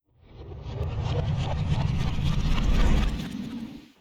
Regular Time Sound Effect.wav